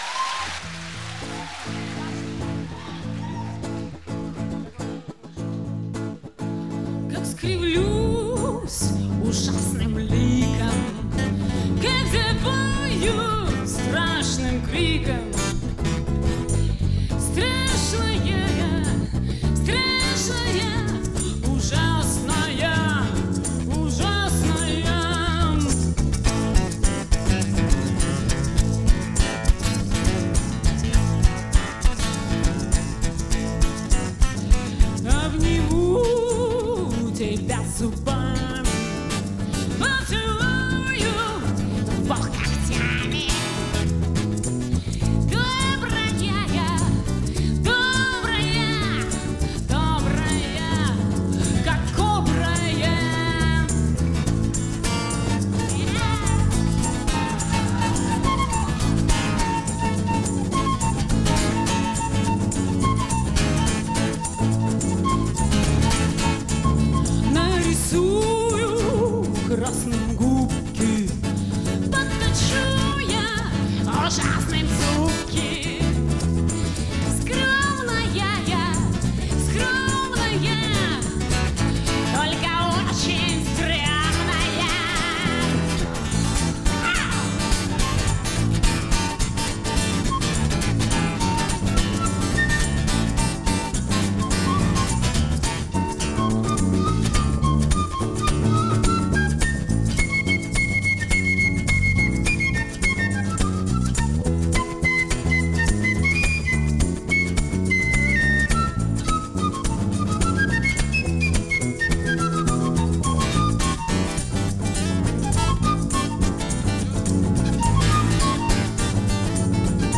Концертный диск, стиль — акустика.
бас, мандолина, голос
саксофон, флейта